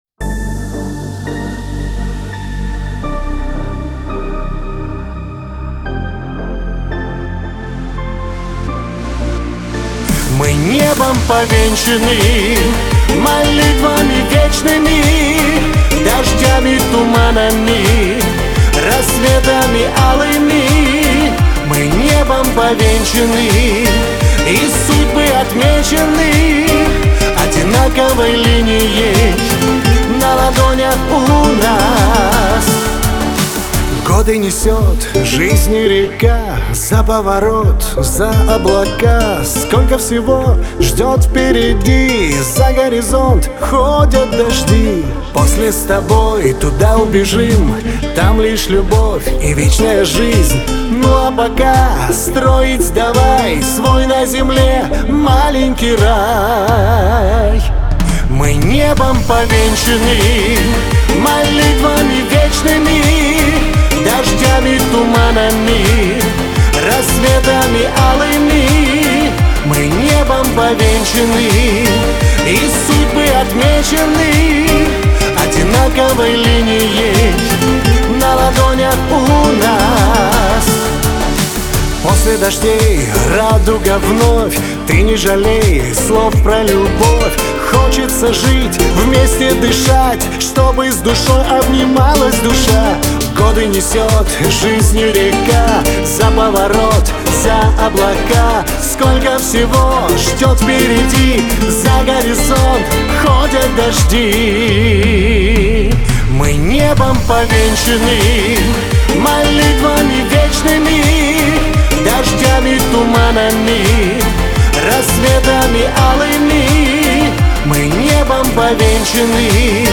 Лирика